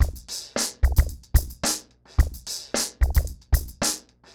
RemixedDrums_110BPM_14.wav